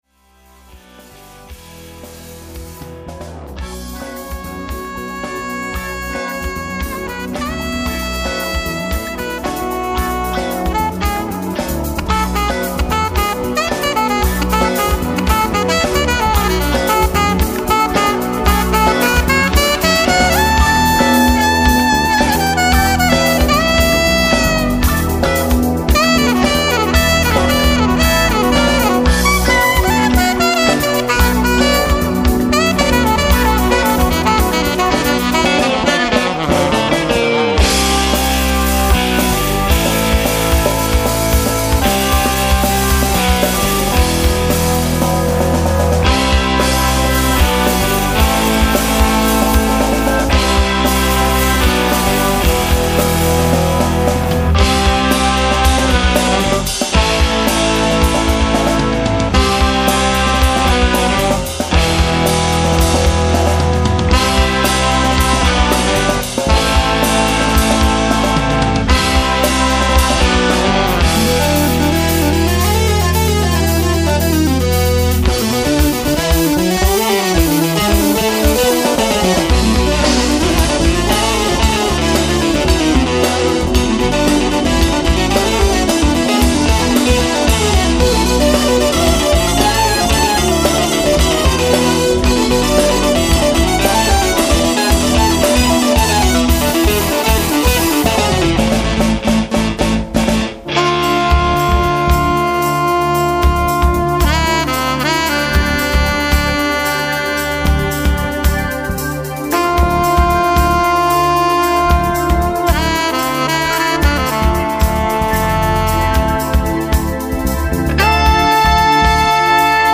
electric & acoustic guitars, vocals
bass, vocals, horns
keyb., organ, moog, vocals
drums, perc